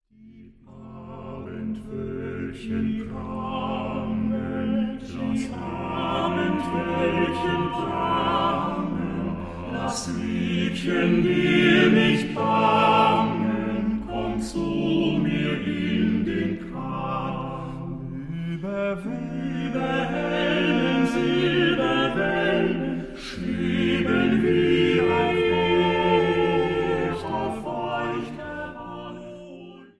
Sextett für 3 Violinen, Viola und 2 Violoncelli op. 38